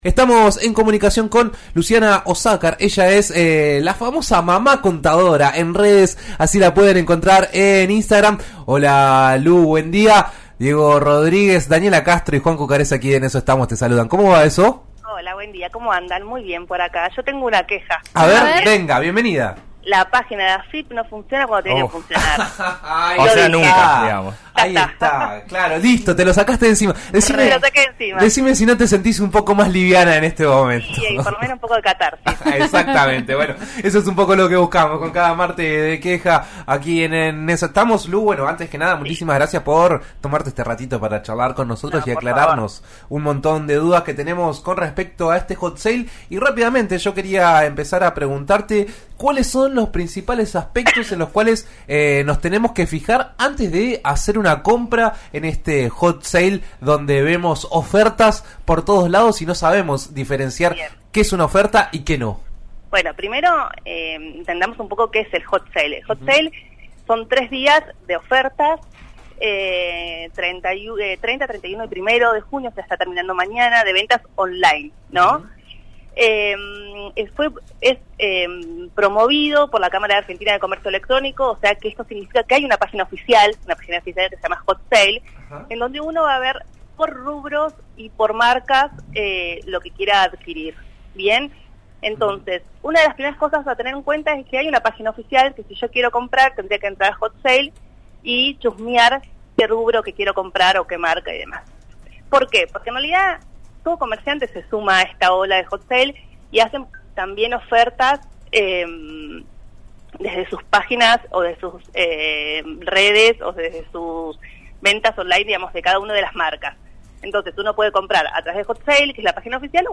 RN Radio (90.9 en Neuquén y 105.7 en Roca)